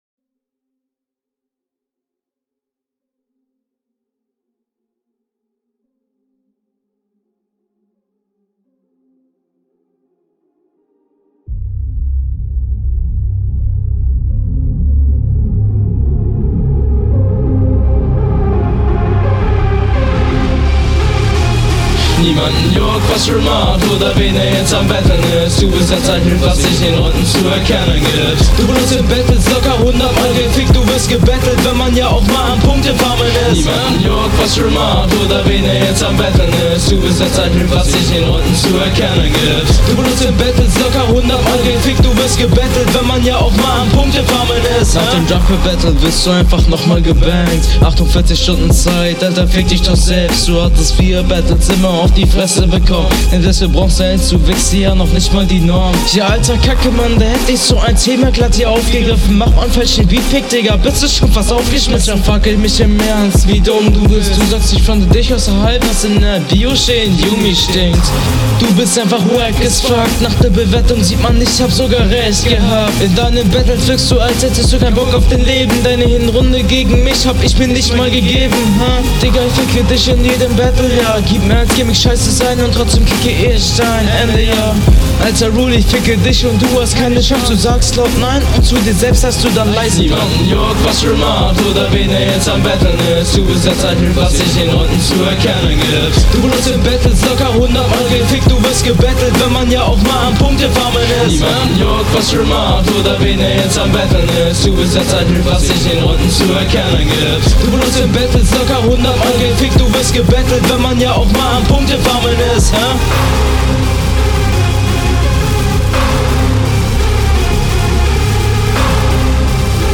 die ersten 15 sekunden hättest du dir sparen können ka mag solche fade in intros …
Ich verstehe nicht alles teilweise wirkt es etwas offbeat im part die hook klingt ganz …
sehr unverständlich. im soundbild sehe ich irgendwo potenzial und ist nicht 0815 aber leider ist …